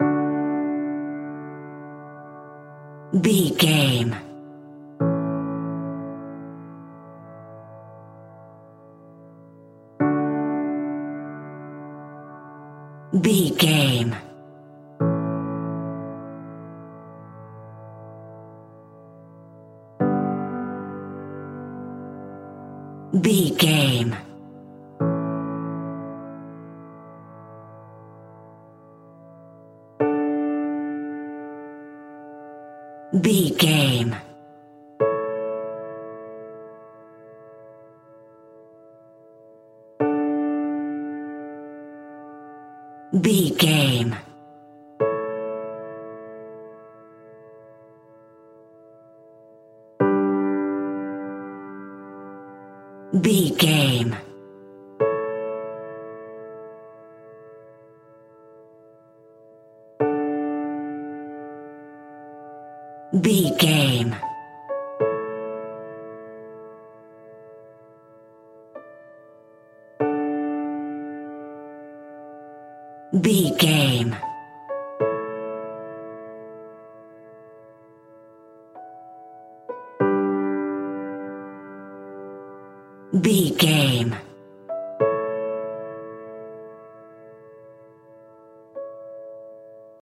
Ionian/Major
Slow
tranquil
synthesiser
drum machine